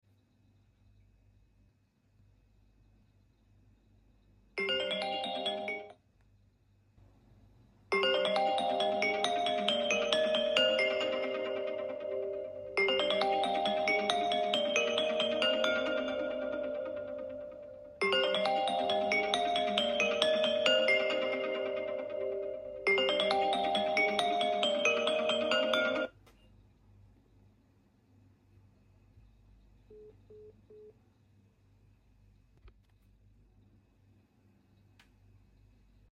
IOS 26 Beta 2 New Sound Effects Free Download